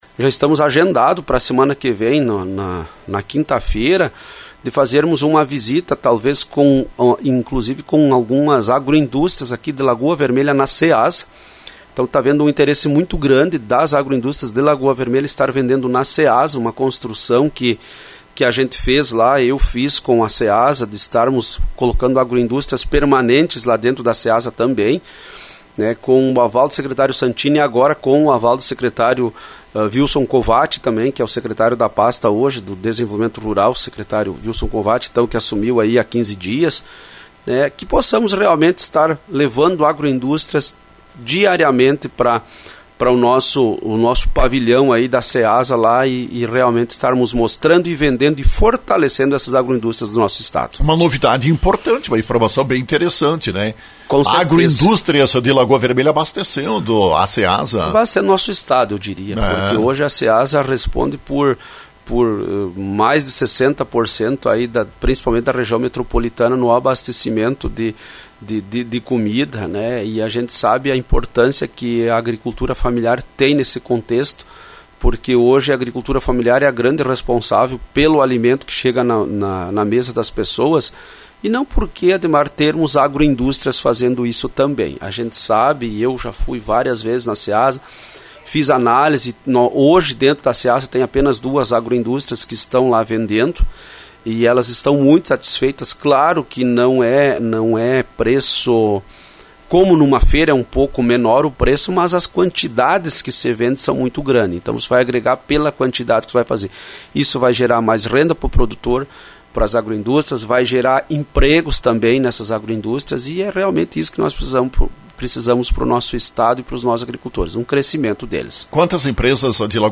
Informação importante transmitida pelo secretário-adjunto da secretaria do Desenvolvimento Rural do Rio Grande do Sul, Lindomar do Carmo Morais: agroindústrias de Lagoa Vermelha poderão fornecer produtos para a Ceasa em porto Alegre. É o que está sendo entabulado.